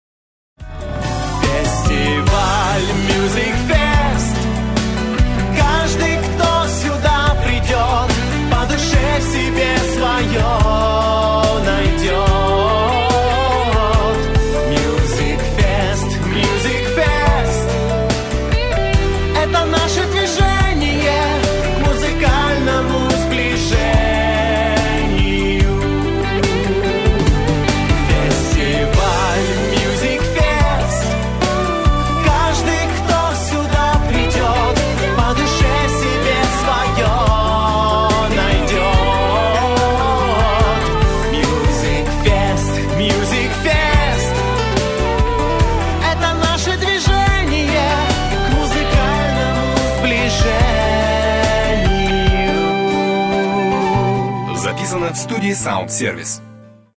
Корпоративный гимн